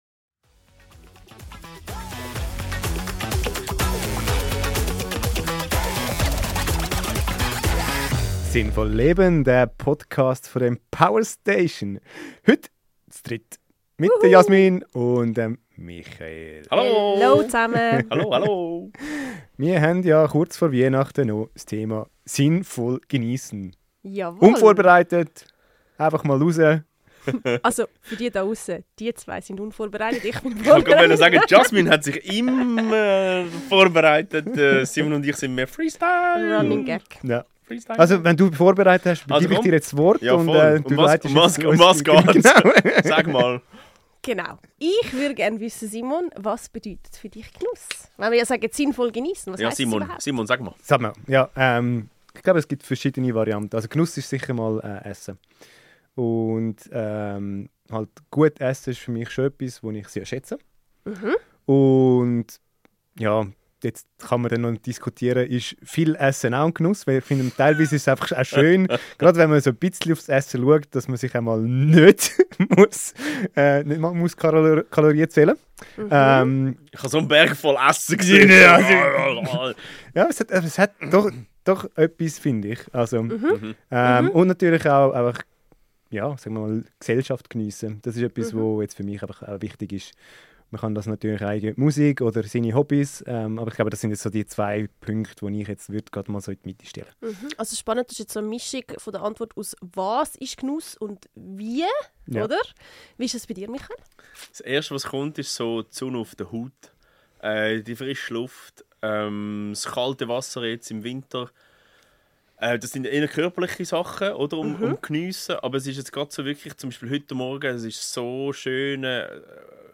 Wir sprechen darüber, warum Genuss oft mit schlechtem Gewissen verwechselt wird, wie Achtsamkeit den Alltag veredelt und wie kleine Momente grosse Wirkung entfalten können. Ein Gespräch über Sinn, Präsenz und die Kunst, das Leben nicht nur zu denken – sondern zu schmecken.